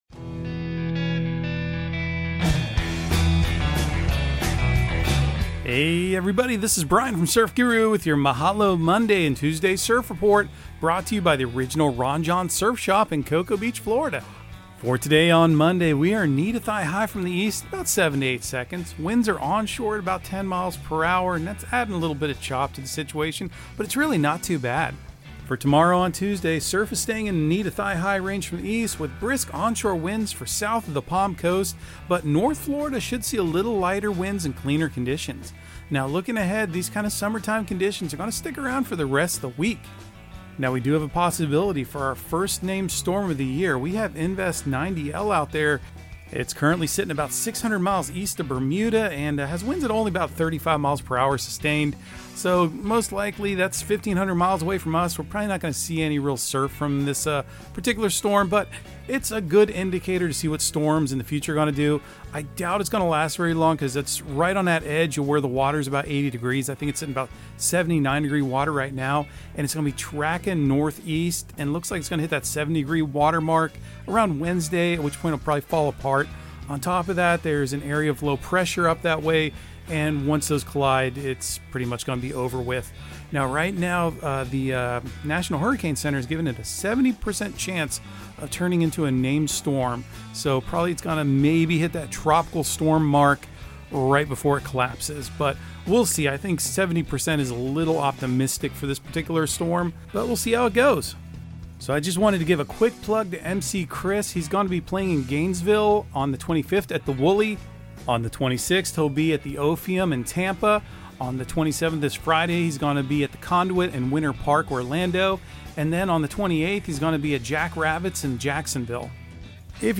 Audio surf report and surf forecast on June 23 for Central Florida and the Southeast. Your host will also enlighten you on current events in the surfing industry and talk about events and entertainment happenings in the local and regional area.